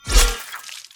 melee-hit-12.mp3